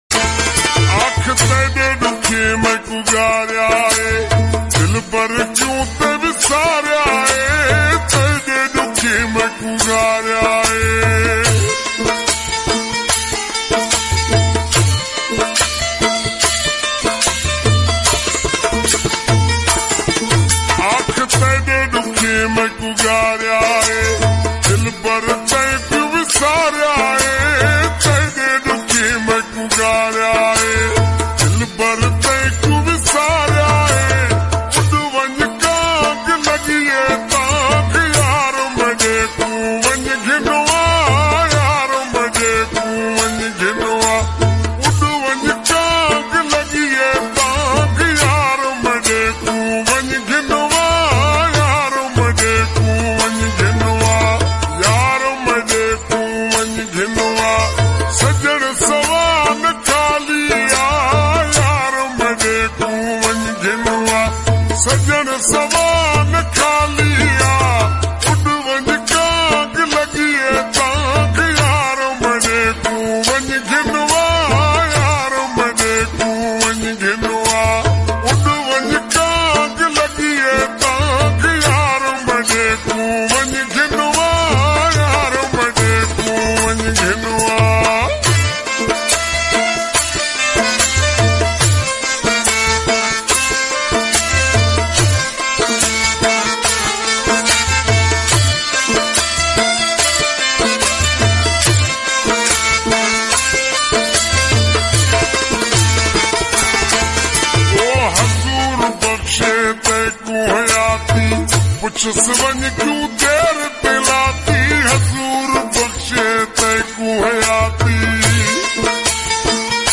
Slow Reward Saraiki Song